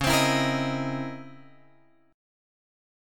C# Minor Major 13th